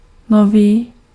Ääntäminen
Ääntäminen Tuntematon aksentti: IPA: /ˈnɔ.viː/ Haettu sana löytyi näillä lähdekielillä: tšekki Käännös Ääninäyte Adjektiivit 1. new UK US US Suku: m .